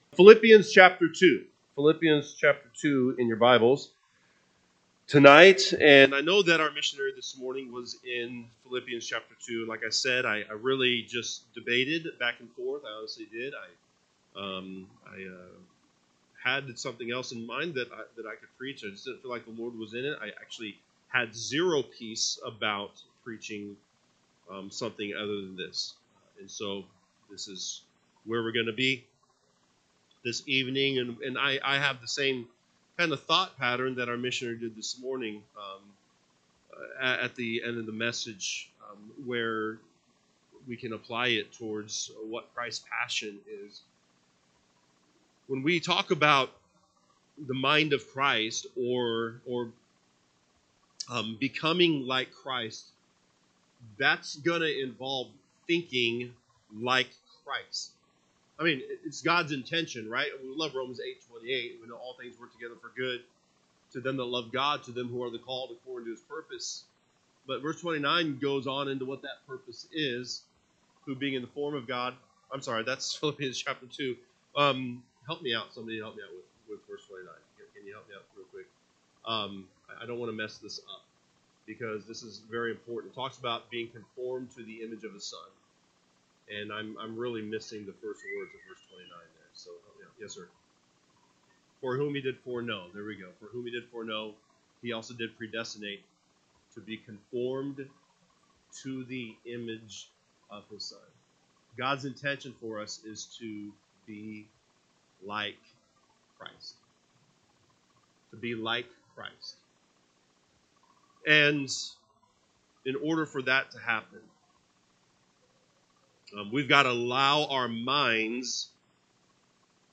September 21, 2025 pm Service Philippians 2:5-11 (KJB) 5 Let this mind be in you, which was also in Christ Jesus: 6 Who, being in the form of God, thought it not robbery to be equal with …
Sunday PM Message